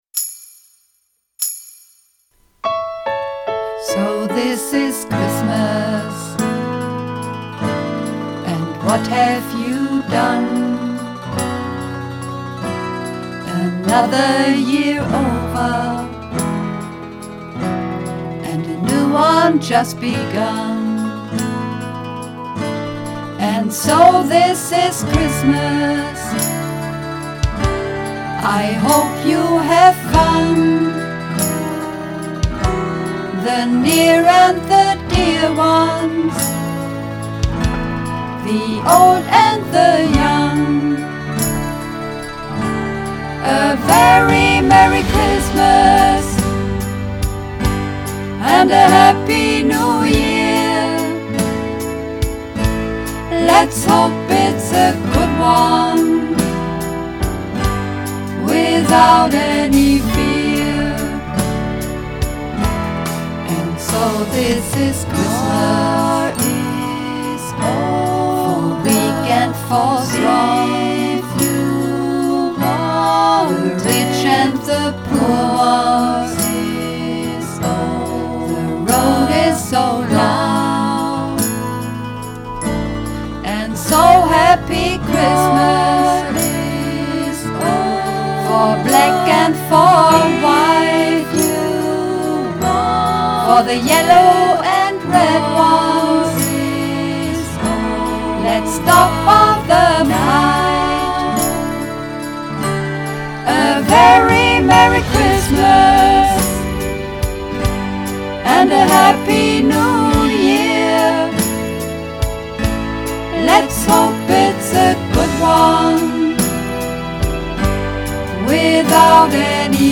(Mehrstimmig)